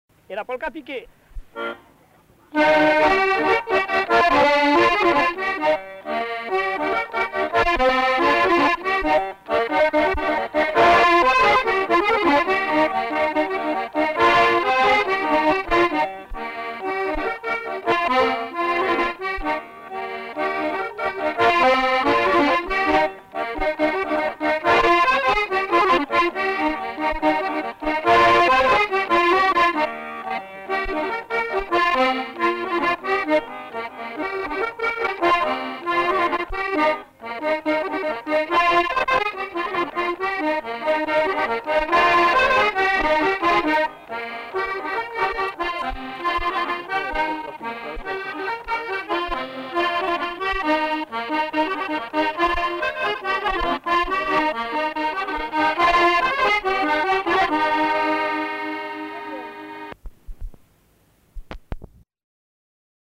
enquêtes sonores
Polka piquée